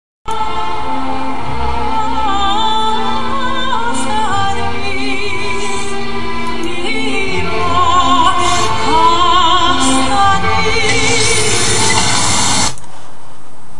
스몰빌 시즌10 16화에 라이오넬루터가 사무실에 앉아있는 장면(34분28초)에 나오는 것을 캡쳐한것이고요.   클래식에 조예가 깊으신분이라면 금방 아실꺼 같습니다.